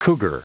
Prononciation du mot cougar en anglais (fichier audio)
Prononciation du mot : cougar